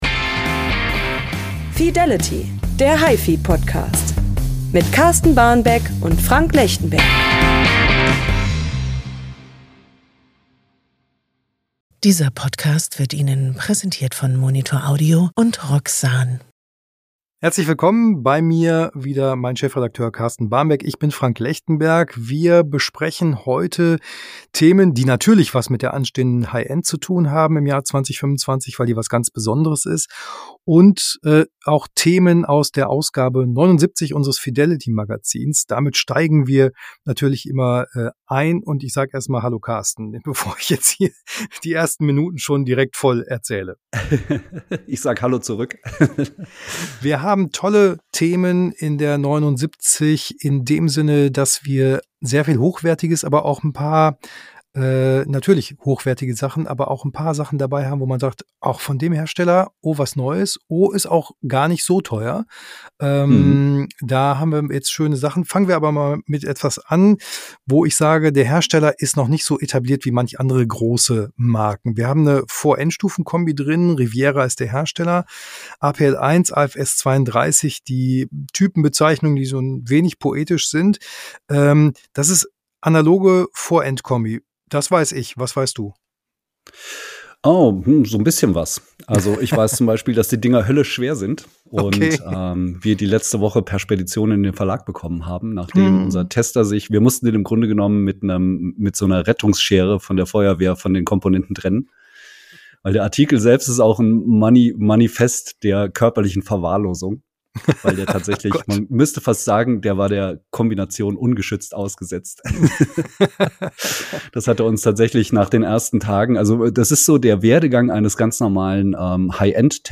Geräte, Gerüchte und gute Musik! Der gepflegte Talk am Kaffeetisch zu unserem liebsten Hobby.